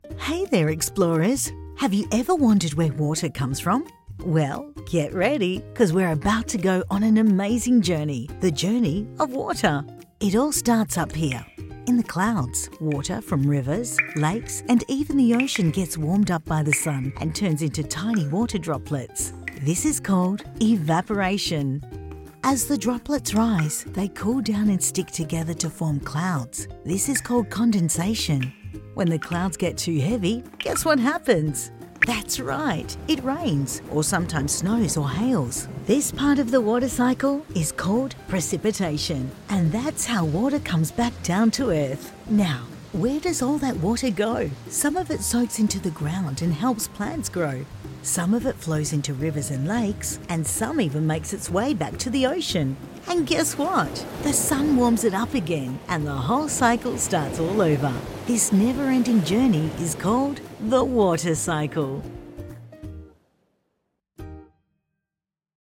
Female
English (Australian), English (Neutral - Mid Trans Atlantic)
Kids Educational Narration
0414Kids_Educational.mp3